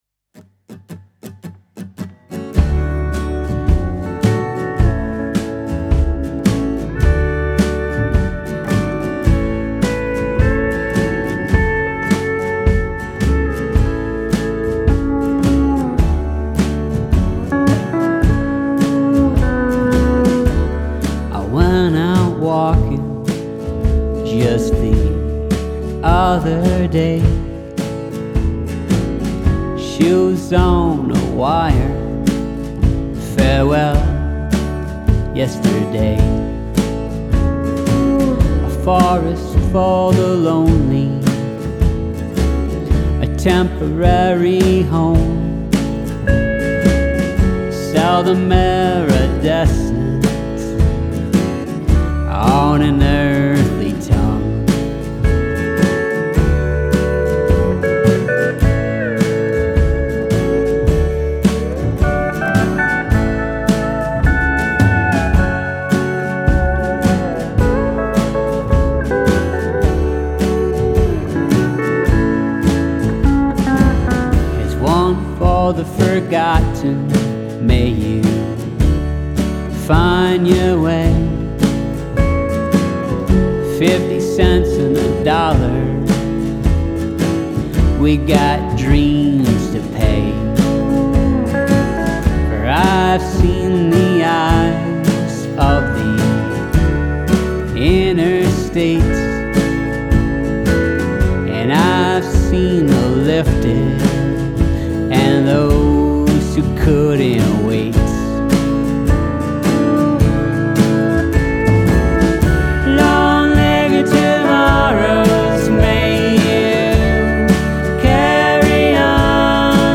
positive vibes and an uplifting message